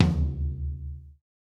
Index of /musicradar/Kit 3 - Acoustic
CyCdh_K3Tom-05.wav